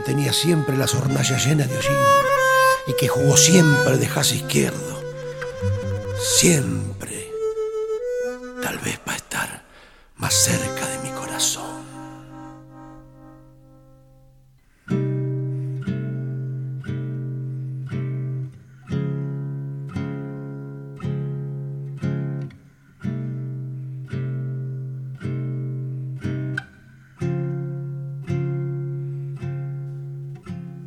voix
harmonica
guitare